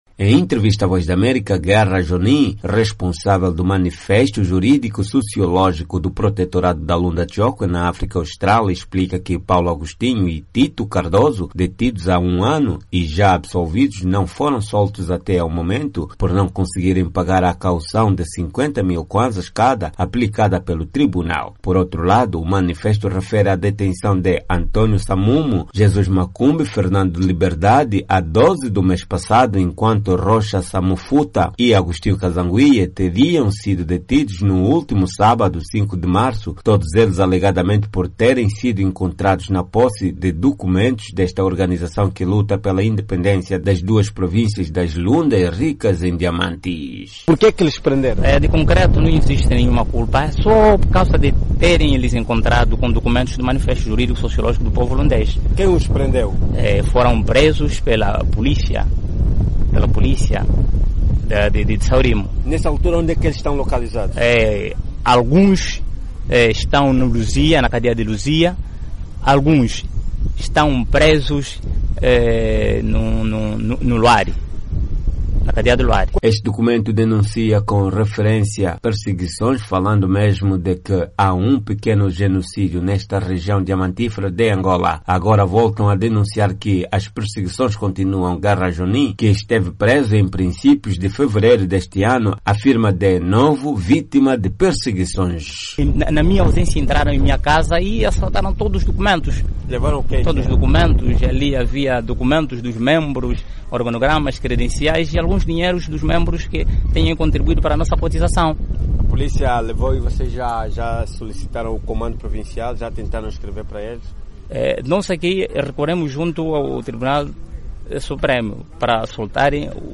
Em entrevista à VOA